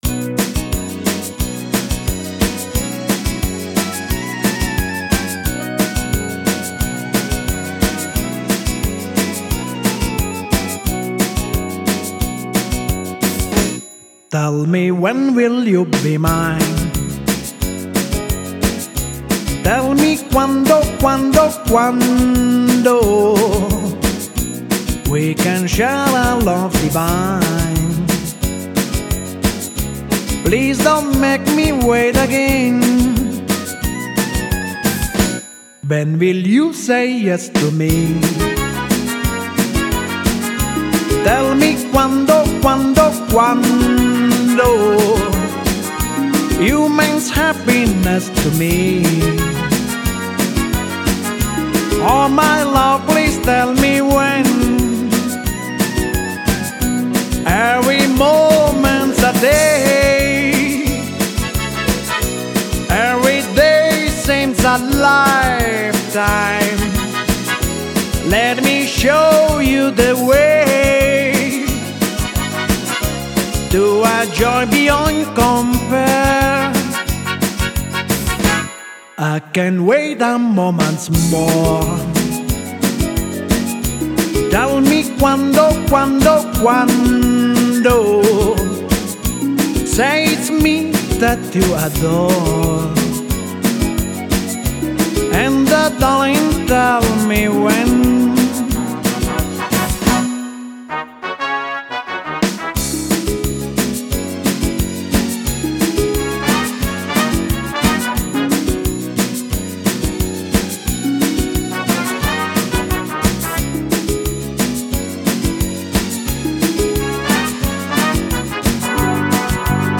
Gesungen